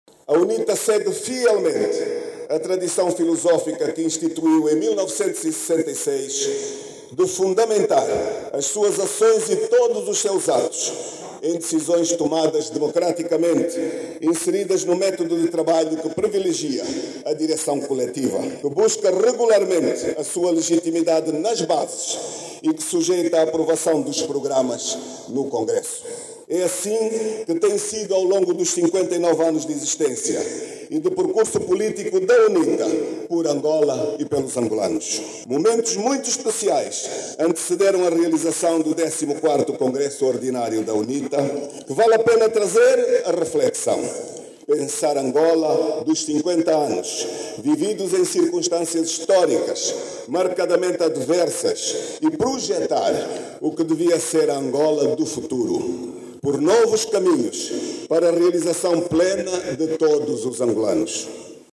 Na cerimónia de abertura do 14.º Congresso da UNITA, durante a sua intervenção, o presidente cessante do partido, Adalberto Costa Júnior, sublinhou que acredita na manutenção e afirmação da organização no período que se avizinha.